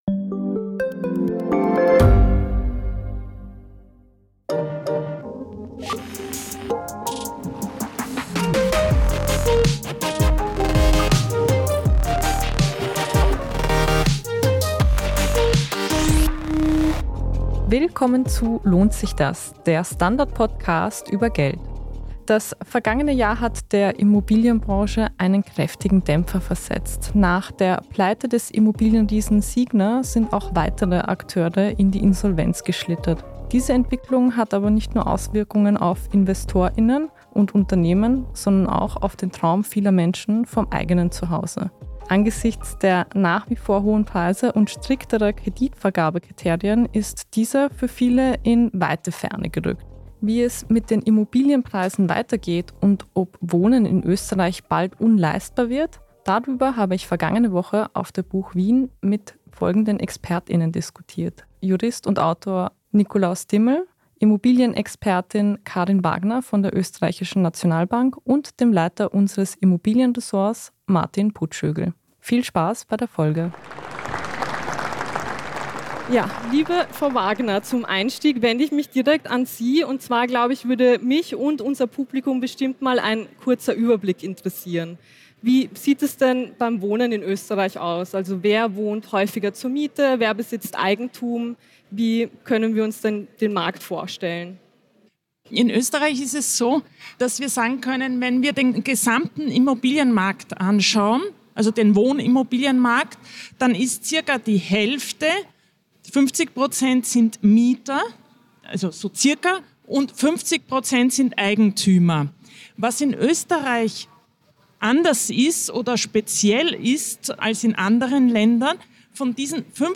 die Livediskussion von der Buch Wien jetzt anhören